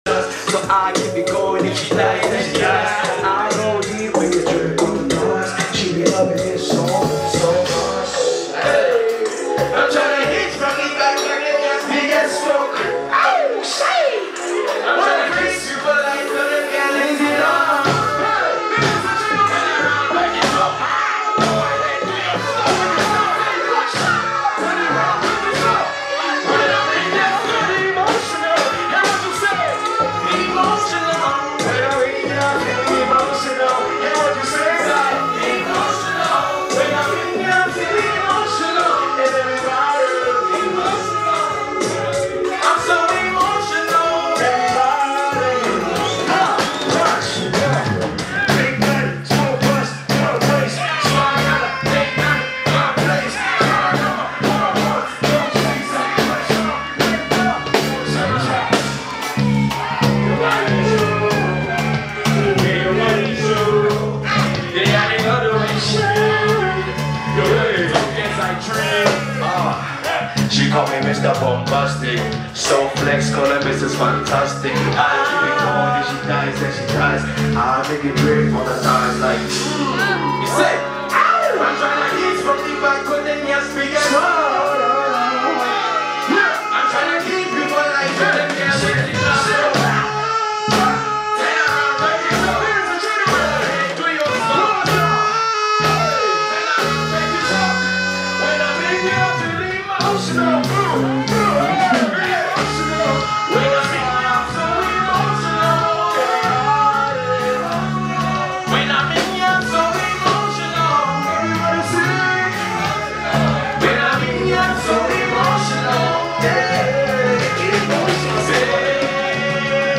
smooth delivery
offers a commanding verse